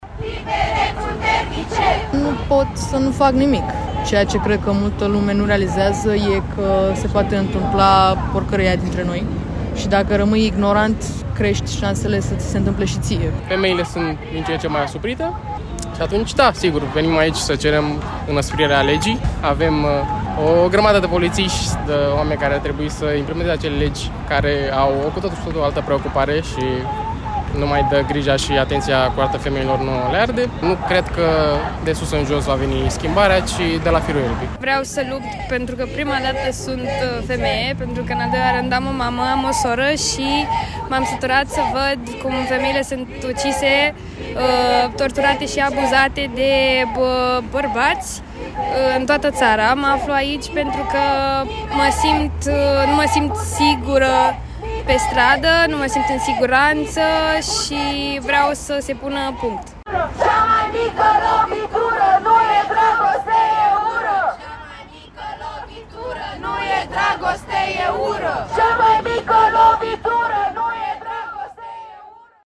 Aproape 200 de persoane au participat marți seară la un protest anti-femicid, în Piața Victoriei din Capitală. Protestul vine după ce un bărbat a ucis o femeie care își ținea în brațe copilul, ignorând ordinele de protecție existente.
„Nu pot să nu fac nimic. Ceea ce cred că multă lume nu realizează e că se poate întâmpla porcăria aia dintre noi, și dacă rămâi ignorant, crești șansele să ți se întâmple și ție”, spune o tânără venită la protest.
Manifestanții au strigat în cor: „Cea mai mică lovitură nu e dragoste, e ură”.